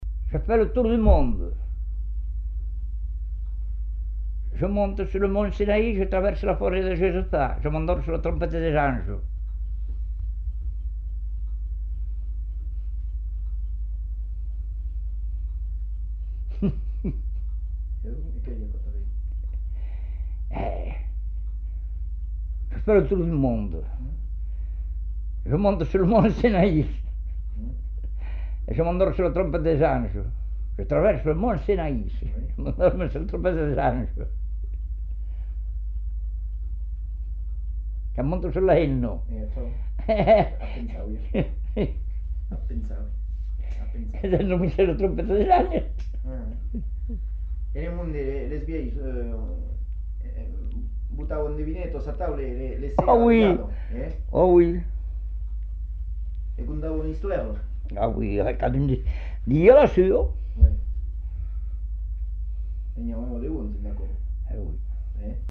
Aire culturelle : Savès
Genre : forme brève
Type de voix : voix d'homme
Production du son : récité
Classification : devinette-énigme